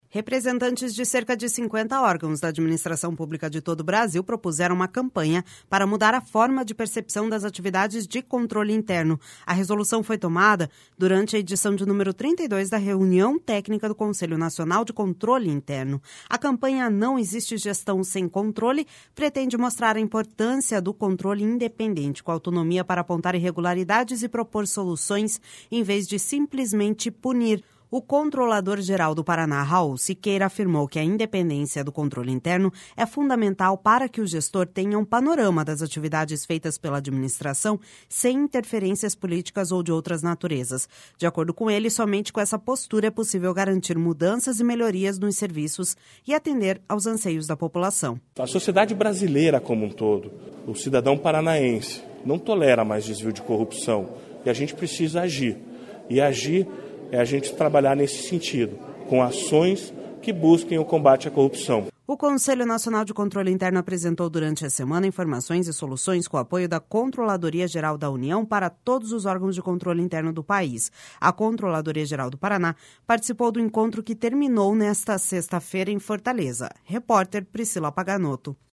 De acordo com ele, somente com essa postura é possível garantir mudanças e melhorias nos serviços e atender aos anseio da população.// SONORA RAUL SIQUEIRA//O Conselho Nacional de Controle Interno apresentou, durante a semana, informações e soluções com o apoio da Controladoria Geral da União, para todos os órgãos de Controle Interno do país.